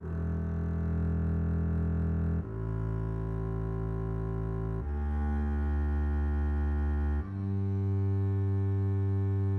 Cordes à vide de la contrebasse. Bien que la contrebasse ne soit pas considérée comme un instrument transpositeur, les notes réelles émises par l'instrument se trouvent une octave en dessous des notes écrites.
Accord_cbasse(contrabasse).mid.mp3